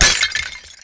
ceramic_impact4.wav